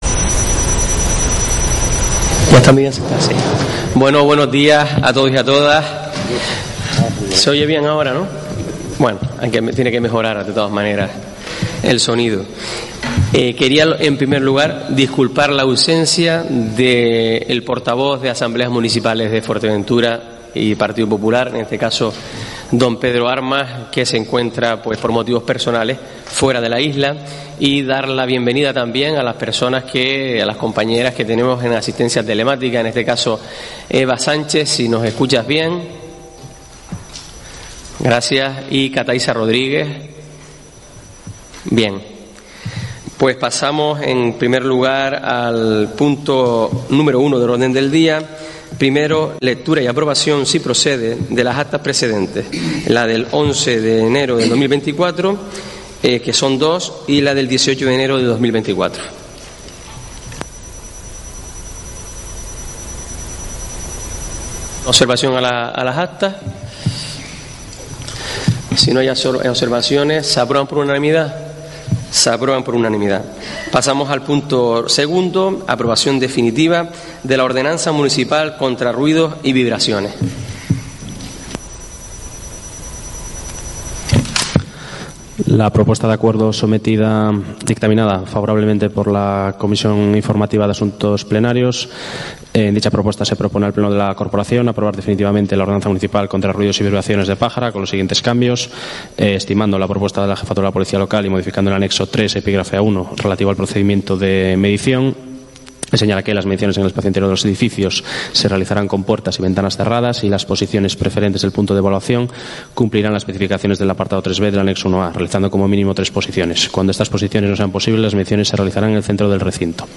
Te traemos en Directo el Pleno Ordinario del Ayuntamiento de Pájara.